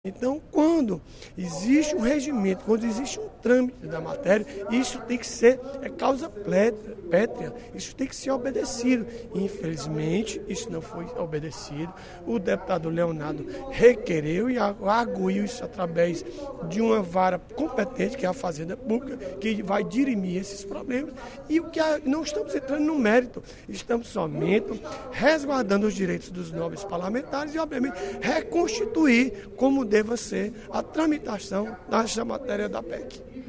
O deputado Odilon Aguiar (PMB) avaliou, durante o primeiro expediente da sessão plenária desta quarta-feira (14/06), a decisão do juiz de direito da 11ª Vara da Fazenda Pública do Estado, que concedeu liminar suspendendo a tramitação da proposta de emenda constitucional (PEC) que extingue o Tribunal de Contas dos Municípios (TCM).